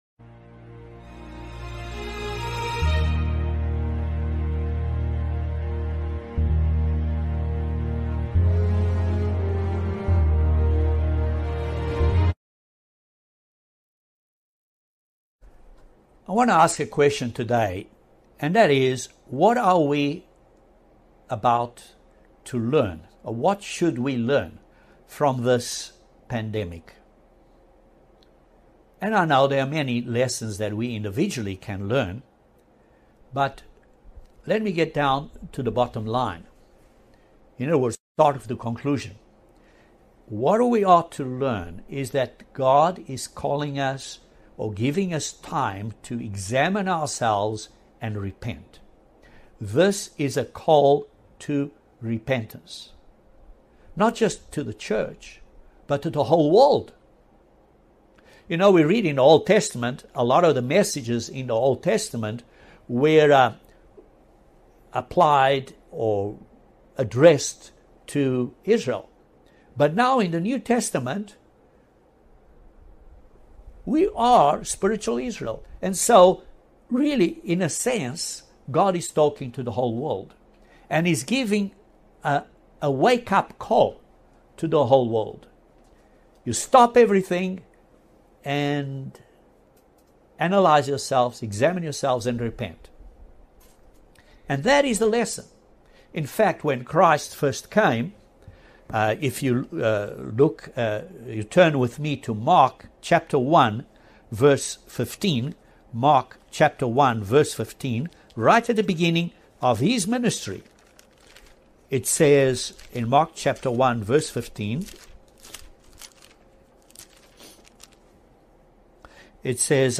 Is this a call to repentance for the world? This question is answered and more, in this eye opening sermon.